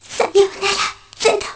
개인적인 용도로 플스용 록맨X4 내부 사운드 데이터 뜯어보던 중에 발견한 음성입니다.
아이리스가 제로에게 작별을 고하는 대사인데 비실거리는 목소리인걸로 보아 죽어갈때 출력되는 대사로 추정되구요.